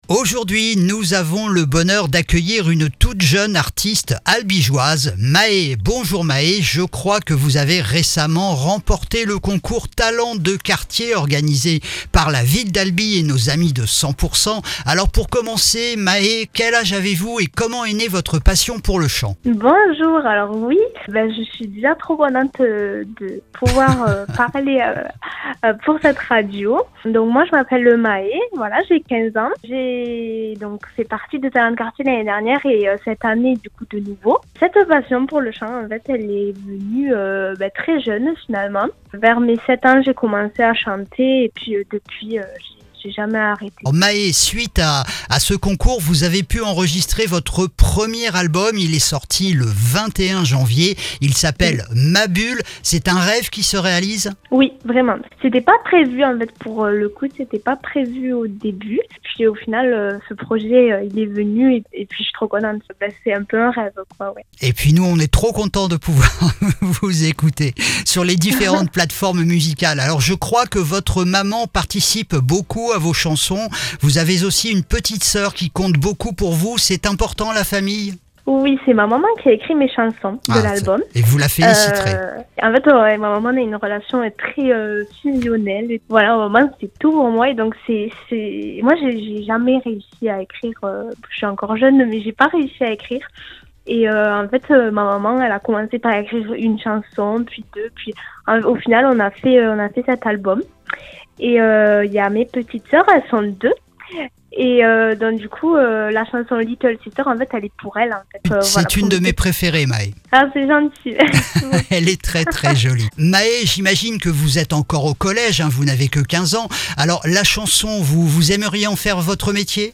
Une jeune chanteuse à écouter absolument !